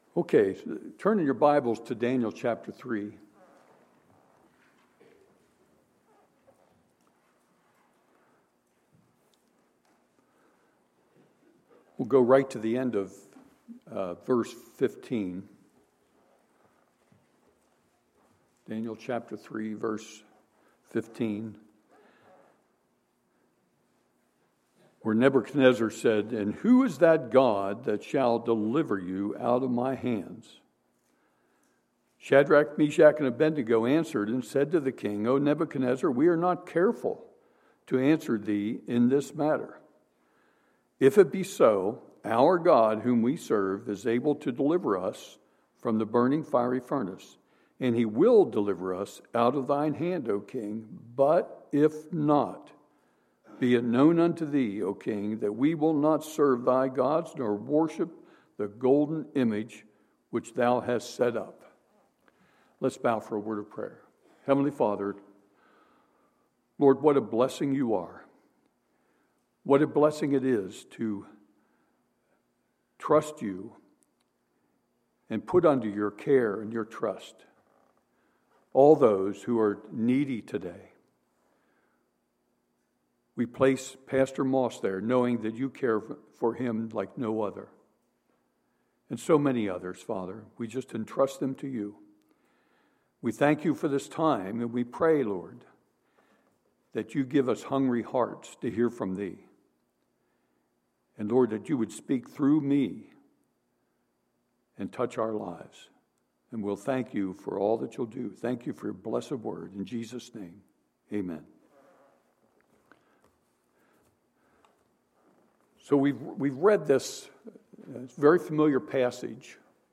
Sunday, January 10, 2016 – Sunday Morning Service